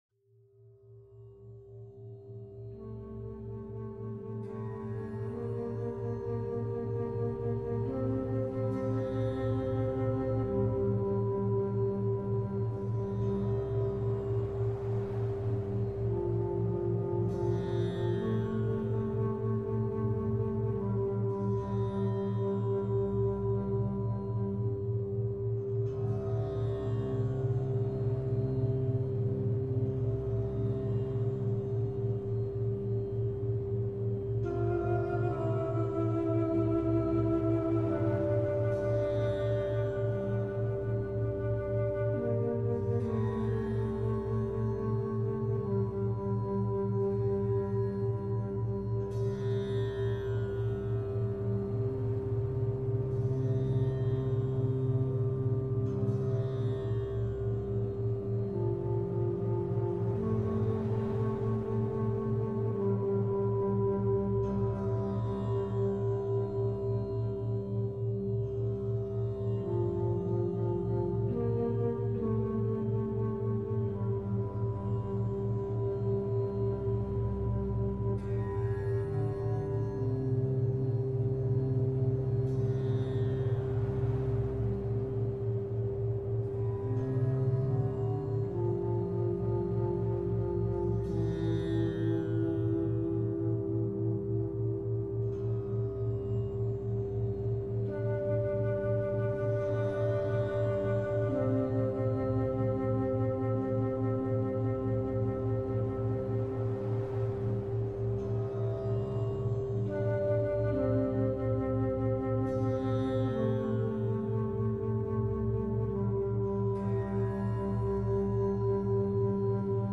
Créneau urbain calme · méthode 25-5 éprouvée pour réussite académique